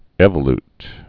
(ĕvə-lt, ēvə-)